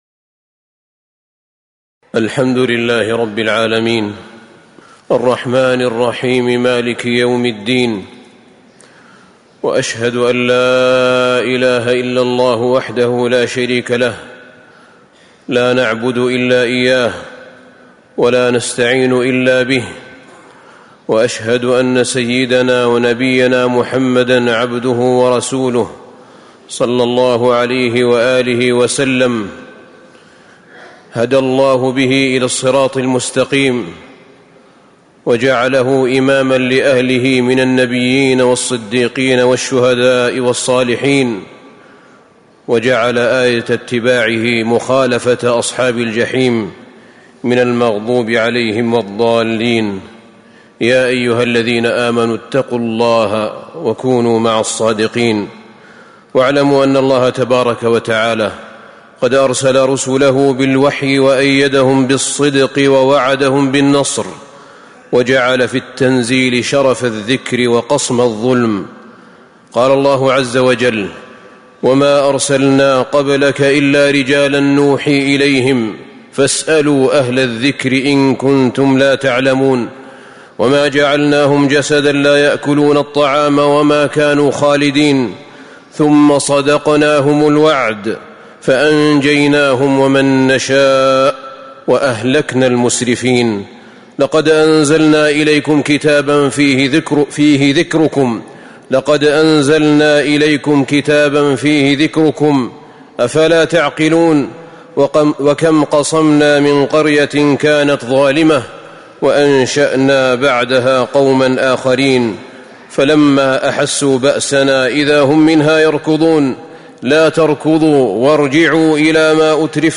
تاريخ النشر ١٩ ربيع الثاني ١٤٤٥ هـ المكان: المسجد النبوي الشيخ: فضيلة الشيخ أحمد بن طالب بن حميد فضيلة الشيخ أحمد بن طالب بن حميد من أسباب النصر والتمكين